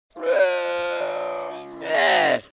Крик верблюда